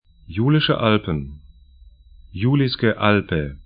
Pronunciation
Julische Alpen 'ju:lɪʃə 'alpn Julijske Alpe 'ju:li:skə 'alpə sl Gebirge / mountains 46°20'N, 14°00'E